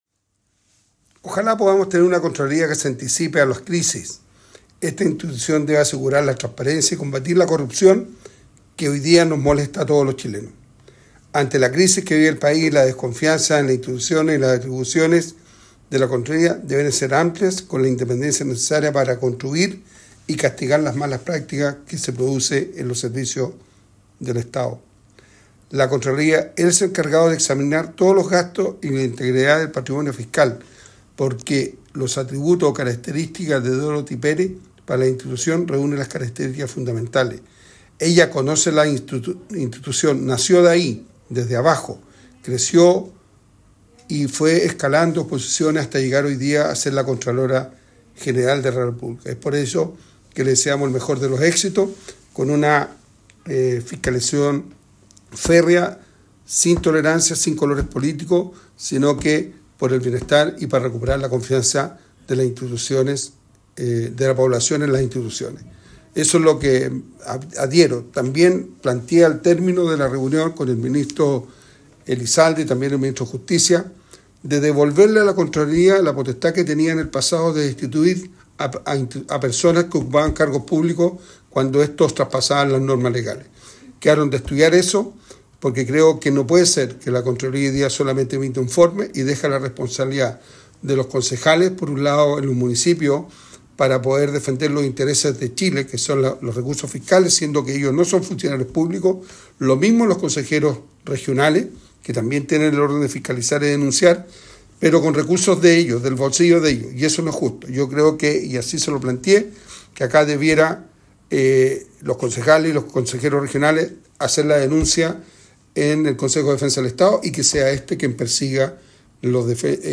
Durante una sesión especial en la Sala del Senado, se aprobó el nombramiento de Dorothy Pérez como Contralora General de la República, en un proceso respaldado por el senador por Atacama, Rafael Prohens. En su intervención, Prohens se refirió a los retos que enfrenta Pérez en su nuevo rol, especialmente en el contexto de la modernización del Estado y el combate contra la corrupción.